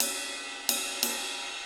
• Crash Cymbal Sound C# Key 06.wav
Royality free crash cymbal drum sample tuned to the C# note.
crash-cymbal-sound-c-sharp-key-06-kKx.wav